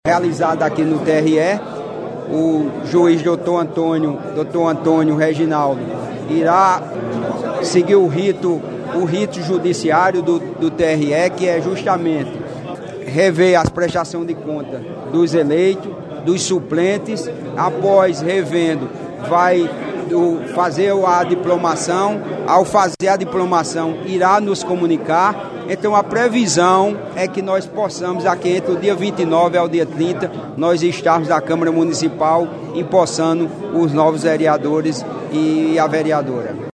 O presidente da Câmara Municipal de Campina Grande, vereador Marinaldo Cardoso, revelou que até o final do mês os quatro vereadores já devem tomar posse.